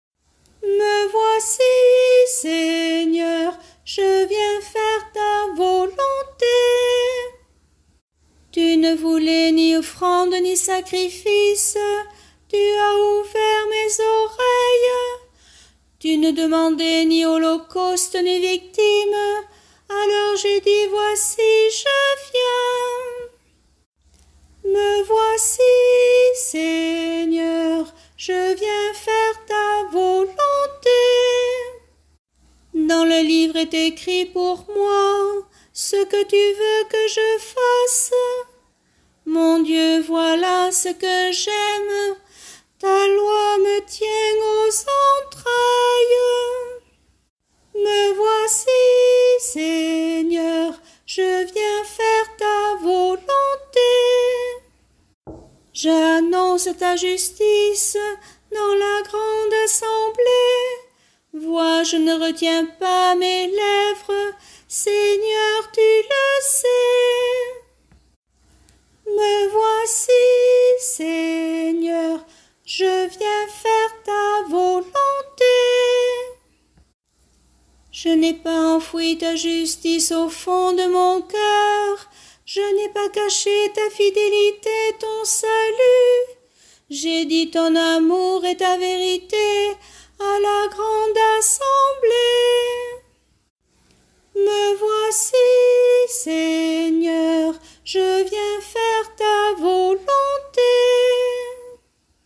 Chorale psaumes année A – Paroisse Aucamville Saint-Loup-Cammas